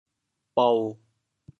③吃食：～啜（饮食；吃喝） 潮州 bou7 白 〈潮〉咀嚼：菜～糜糜正易消化（把菜嚼烂烂的才容易消化）。
bou7.mp3